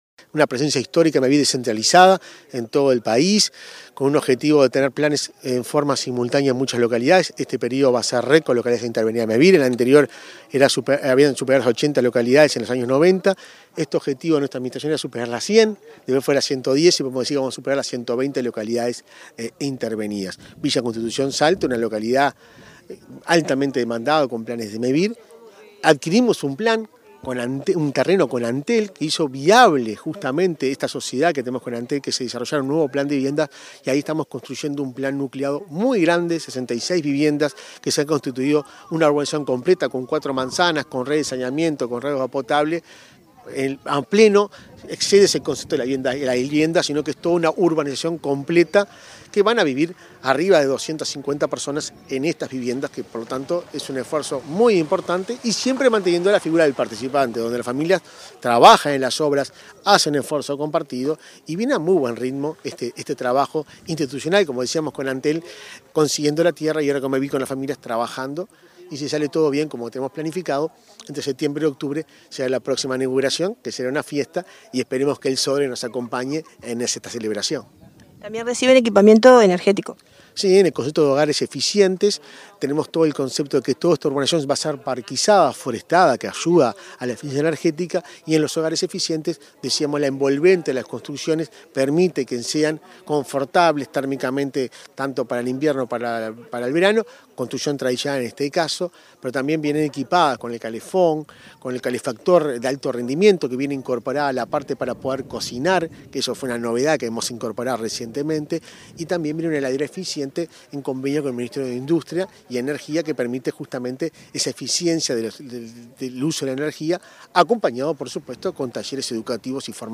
Entrevista al presidente de Mevir, Juan Pablo Delgado